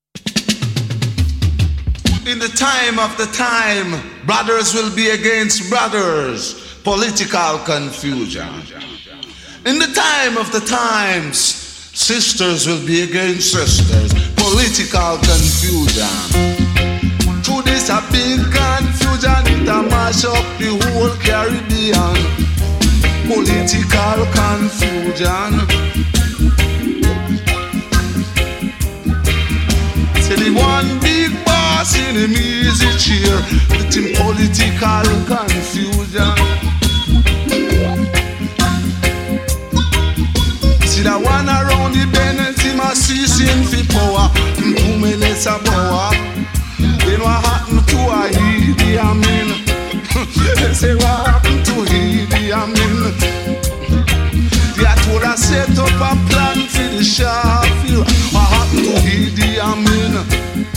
killer deejay version